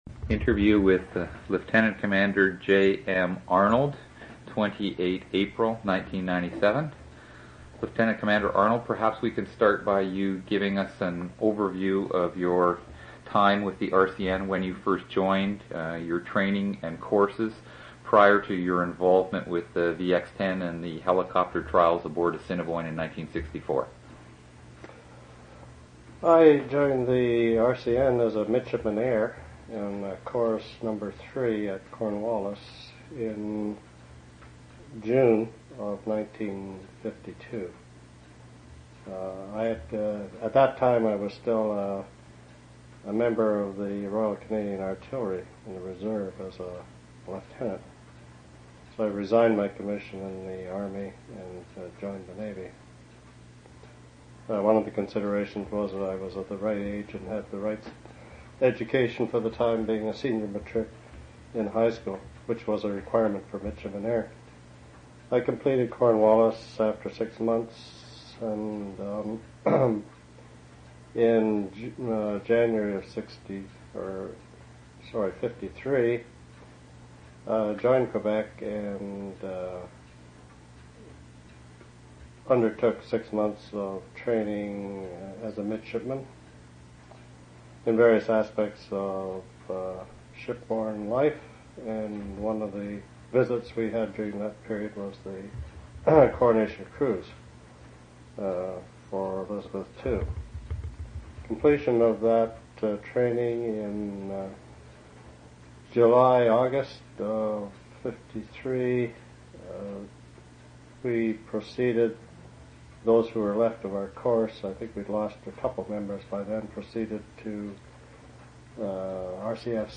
Two original audio cassettes in Special Collections.
oral histories (literary genre) sound recordings interviews reminiscences